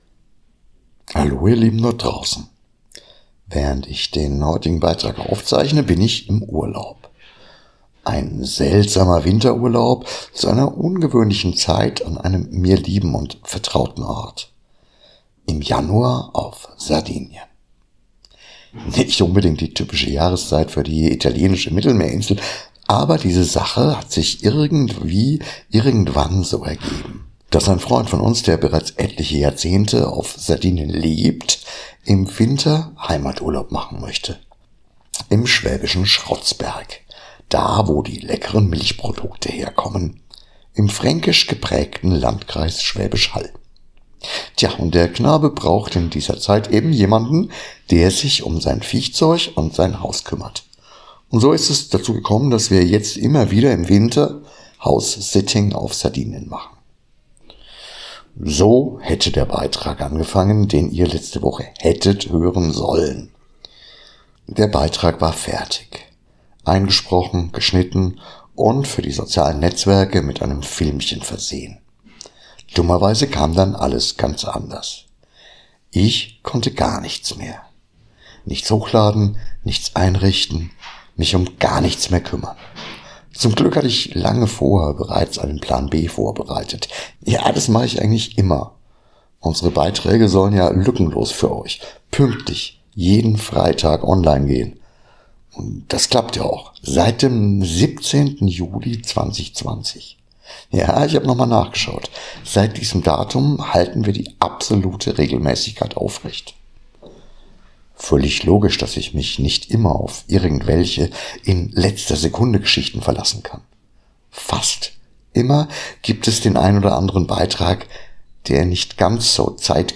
Die völlig ungeplante und vor allem ungeahnte Folge live aus dem Krankenhaus...
+ ENTSCHULDIGT BITTE DIE SCHLECHTE SOUNDQUALITÄT + LIVE AUS DEM KRANKENHAUS +